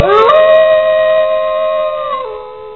Play, download and share Wolfy Sound original sound button!!!!
wolf8_lZrKU8J.mp3